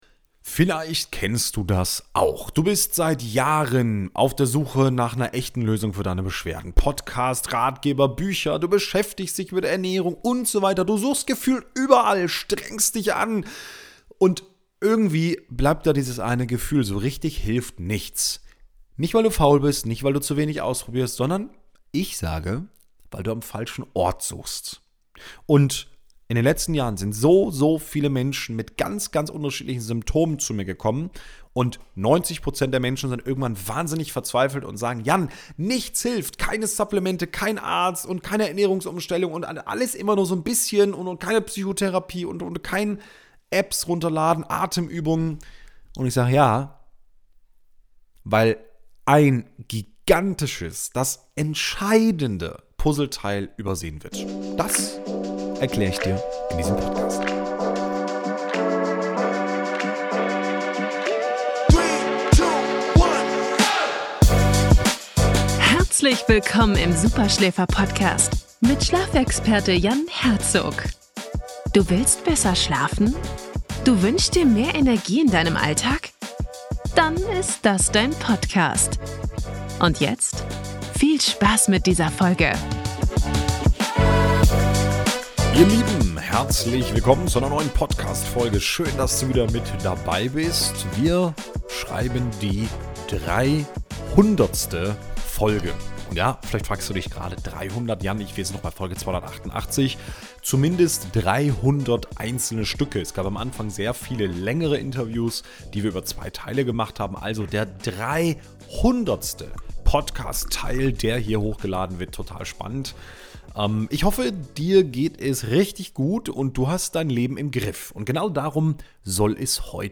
In dieser exklusiven Lesung